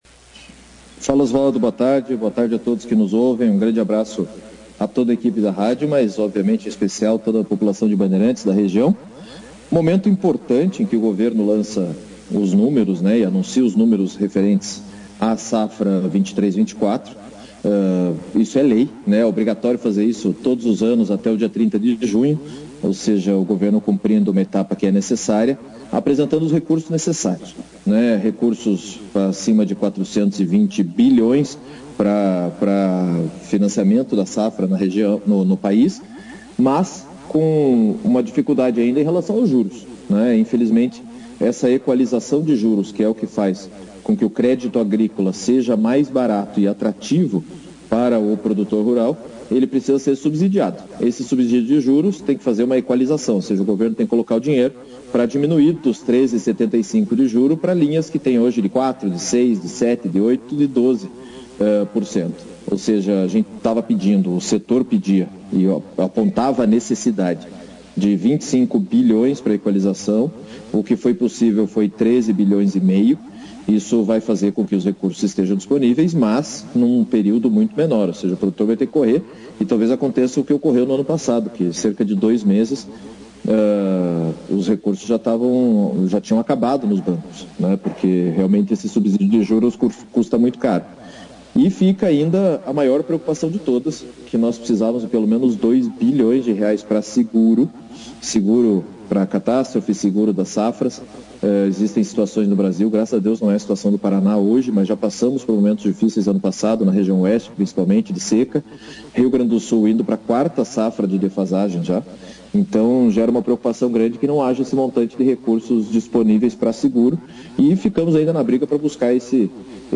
O presidente da Frente Parlamentar da Agropecuária (FPA), deputado federal Pedro Lupion, (foto), participou ao vivo neste sábado, 01/07, do Jornal Operação Cidade, na entrevista ele falou sobre a recepção da frente ao Plano Safra 2023/2024, lançado recentemente pelo governo e de outros assuntos relevantes em discussão no congresso.